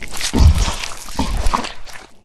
boar_eat_0.ogg